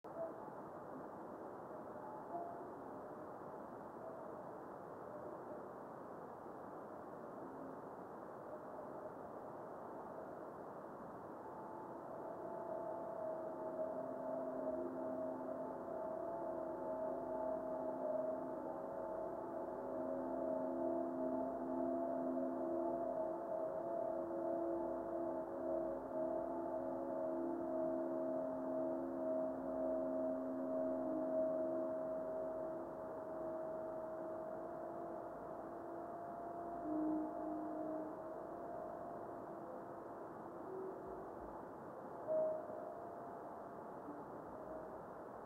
video and stereo sound:
Strong ascending reflection after weak head echo.
Radio spectrogram of the time of the above meteor.  61.250 MHz reception above white line, 83.250 MHz below white line.